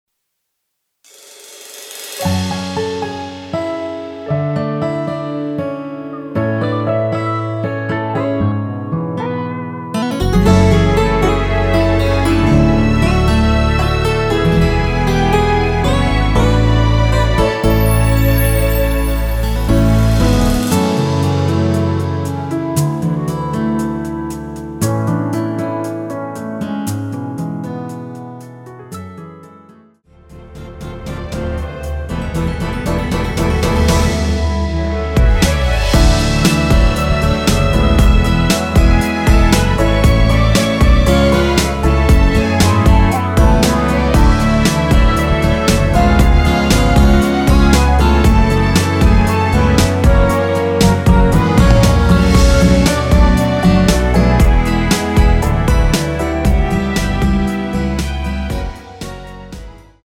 원키에서(-3)내린 멜로디 포함된 MR입니다.(미리듣기 참조)
F#m
앞부분30초, 뒷부분30초씩 편집해서 올려 드리고 있습니다.
중간에 음이 끈어지고 다시 나오는 이유는